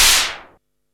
SIMMONS SDS7 2.wav